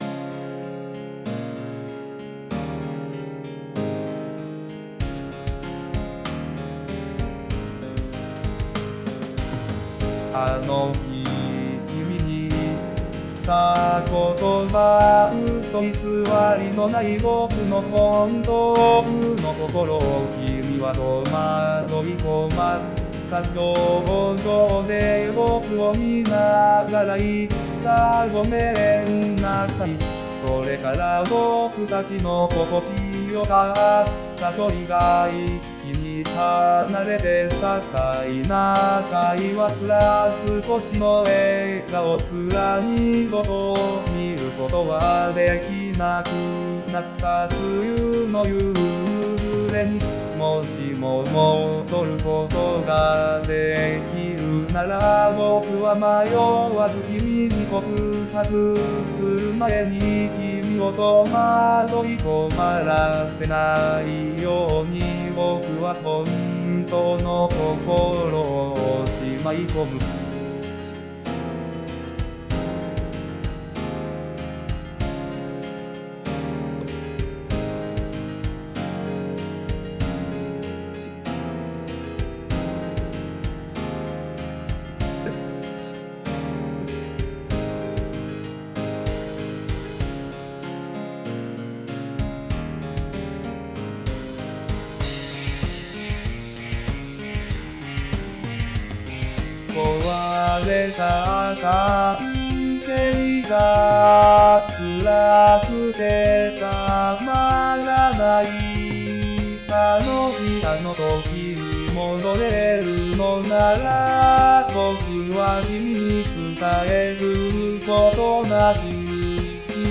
日本語歌詞からAI作曲し、伴奏つき合成音声で最長10分歌います。